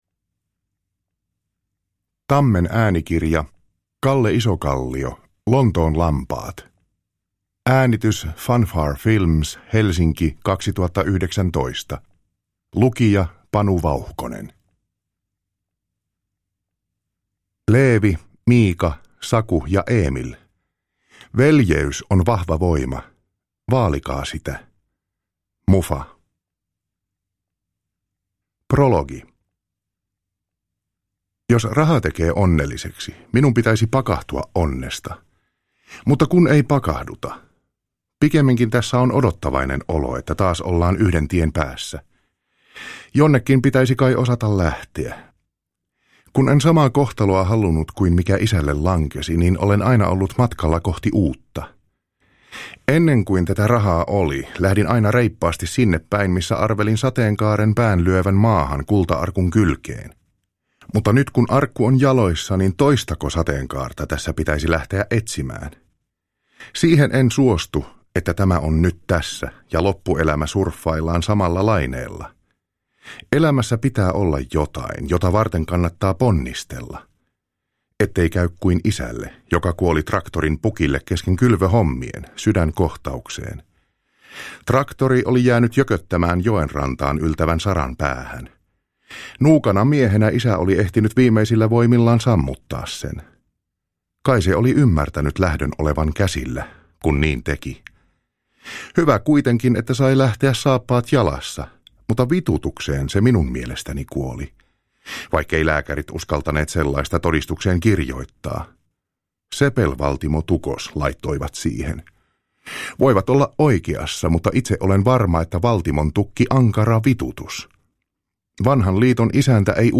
Lontoon lampaat – Ljudbok